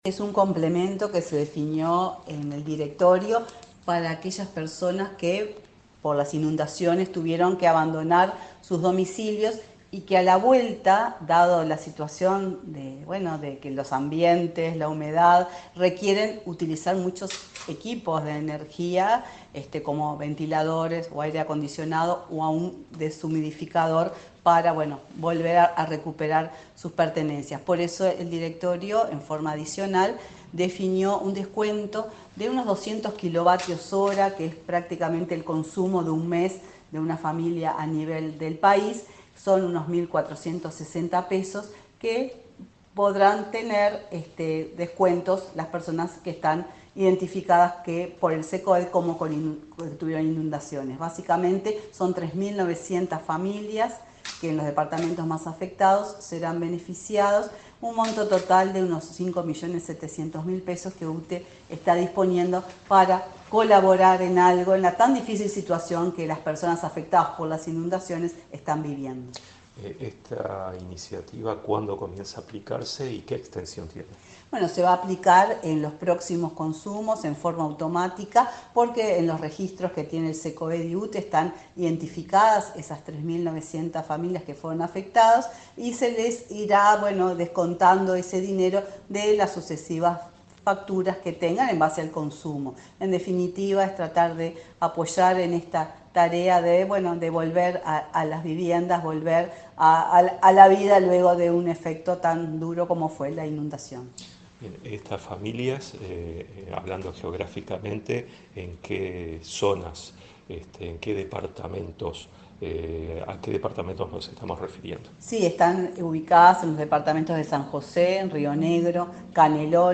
Entrevista a la presidenta de UTE, Silvia Emaldi